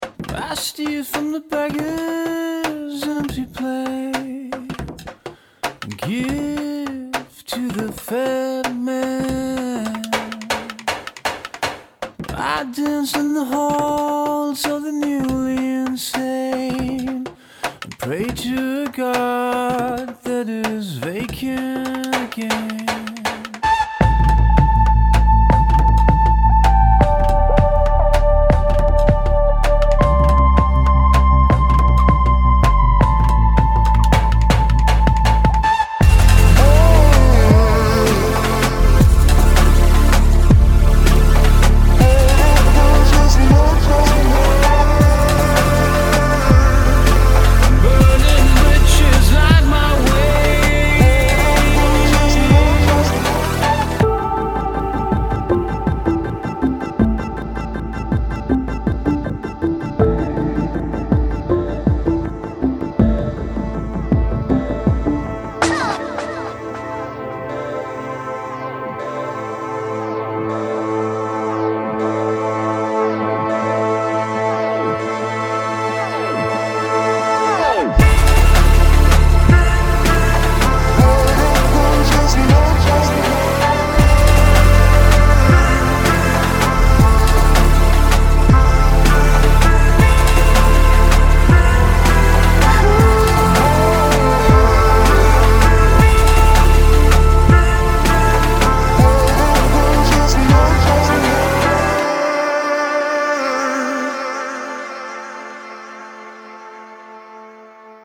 BPM160
Audio QualityPerfect (High Quality)
fast paced track
German house supergroup